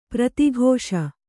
♪ prati ghōṣa